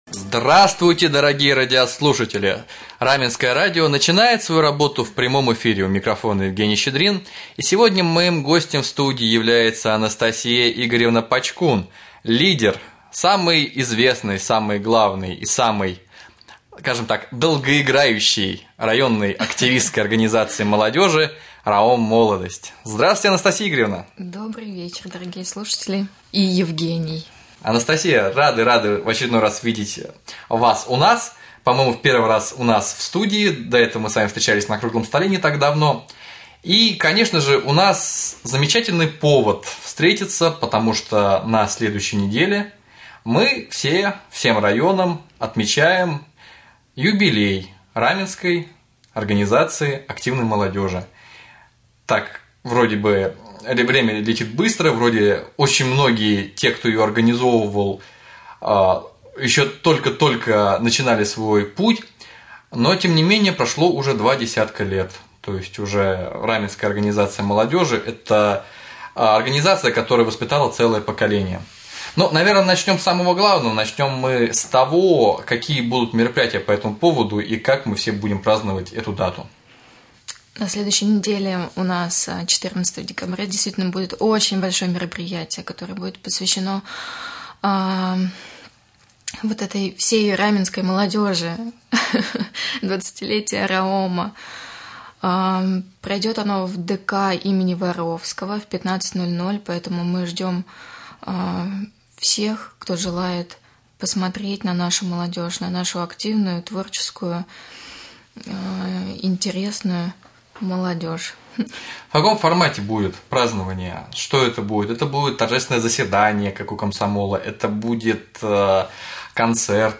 Прямой эфир